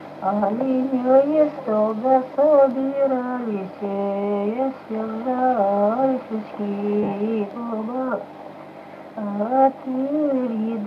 Произнесение частицы –ся в возвратных формах глаголов как –се